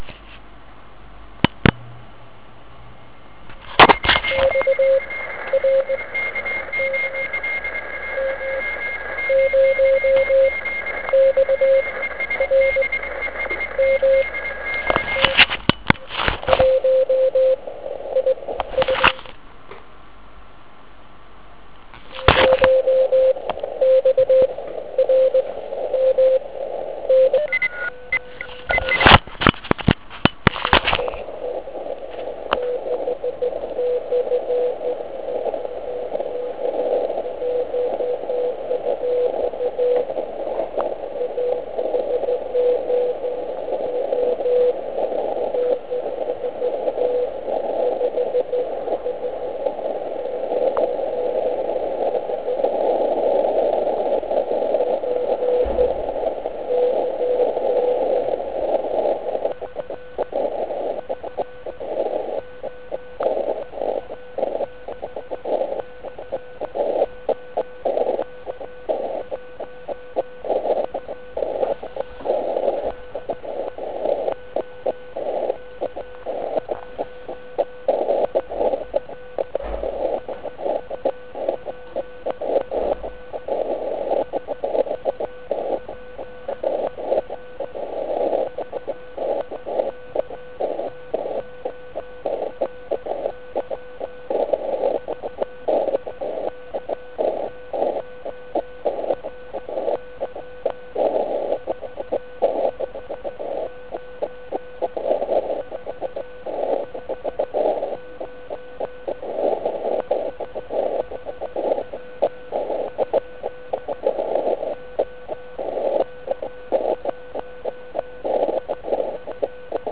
Všimněte si na nekázeň jedné stanice MM0..., která mne volala i když jsem vyvolával jen "SKED".
Připadám si nyní jako kdybych měl spojení s trosečníkem na kře ledové HI.